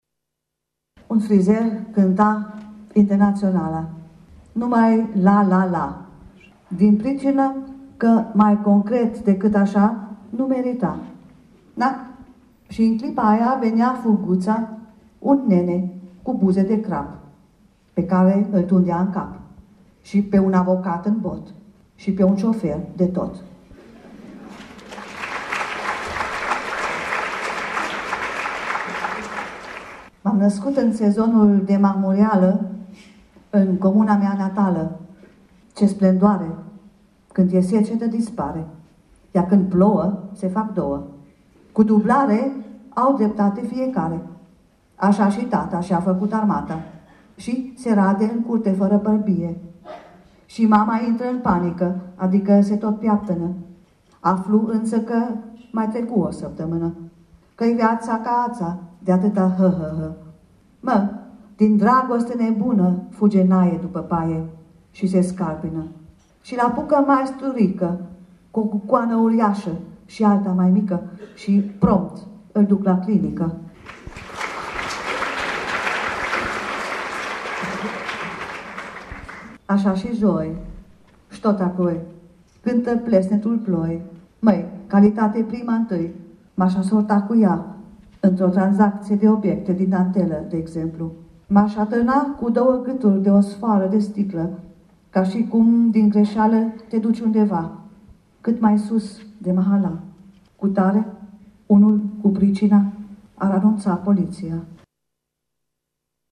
Ne bucurăm, în seara asta, de la ora 22:00, în cadrul emisiunii „Vitralii”, de lectura Hertei Muller, iar pentru „eternitate” păstrăm aici 2-3 poeme audio: